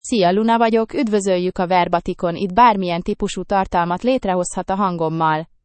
LunaFemale Hungarian AI voice
Luna is a female AI voice for Hungarian (Hungary).
Voice sample
Listen to Luna's female Hungarian voice.
Female